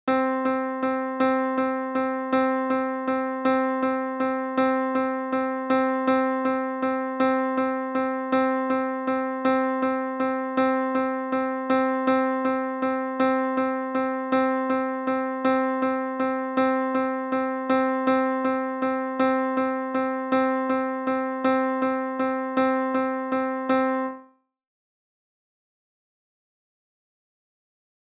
Feel the acceleration in the line?
What we're looking to do here is to use accents to group the four 16th's into three's ... groups of triplets?